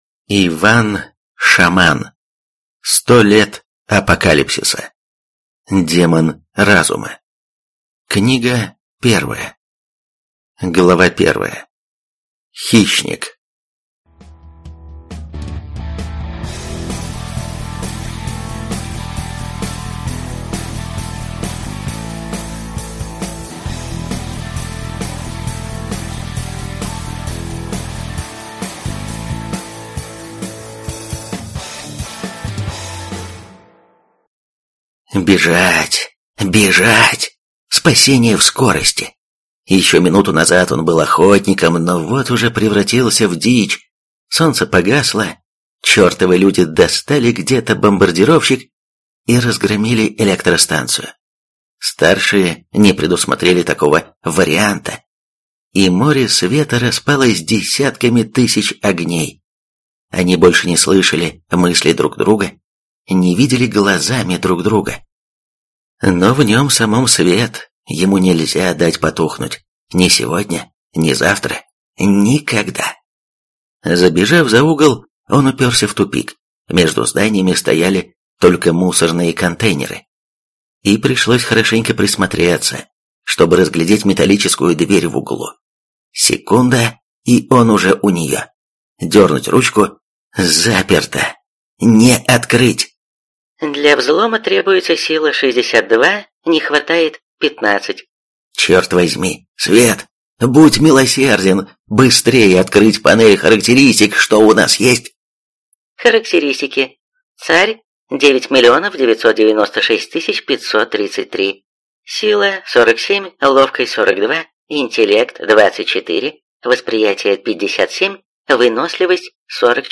Аудиокнига Демон Разума. Книга 1 | Библиотека аудиокниг